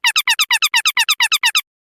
Play Cartoon Squeaky Toy - SoundBoardGuy
Play, download and share Cartoon Squeaky Toy original sound button!!!!
cartoon-squeaky-toy-sound-effect-funny-sound-effects-no-copyright.mp3